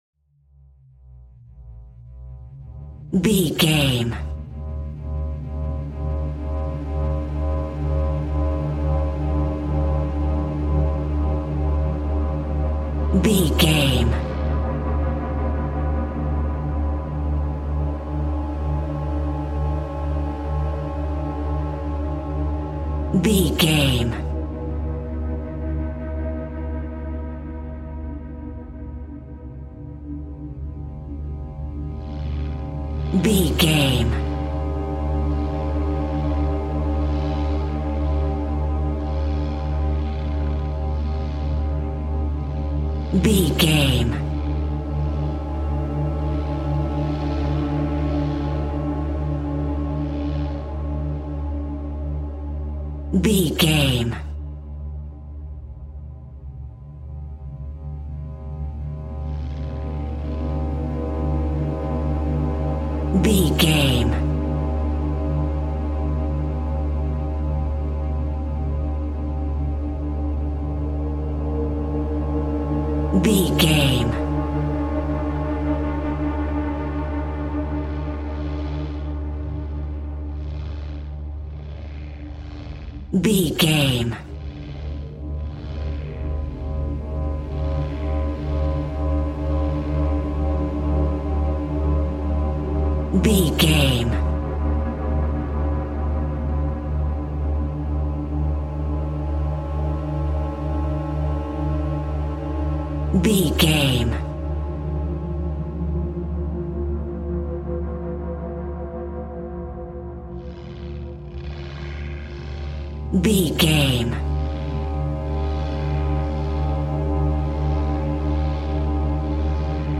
Atonal
Slow
ominous
dark
suspense
eerie
synthesiser
horror